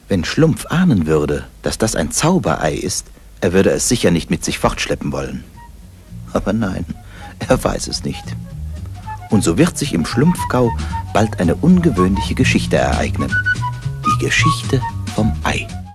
Erzähler: